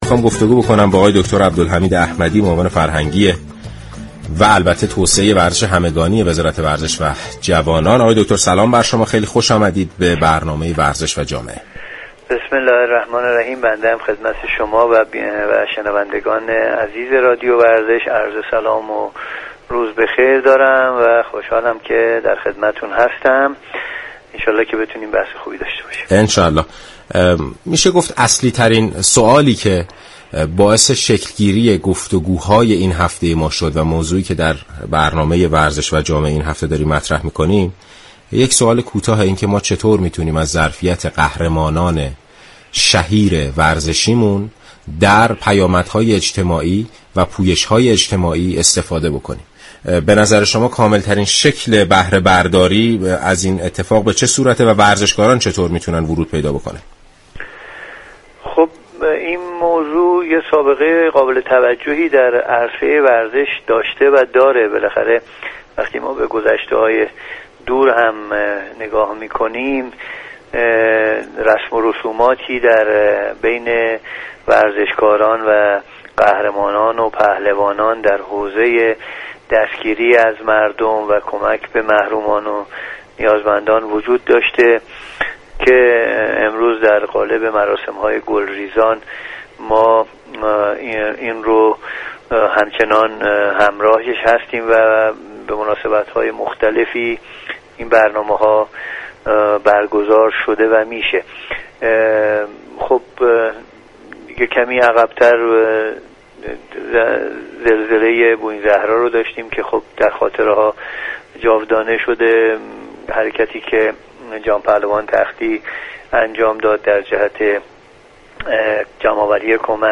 شما مخاطب محترم می توانید از طریق فایل صوتی پیوست شنونده ادامه صحبت های دكتر احمدی، معاون فرهنگی و توسعه ورزش همگانی وزارت ورزش و جوانان در برنامه ورزش و جامعه رادیو ورزش باشید.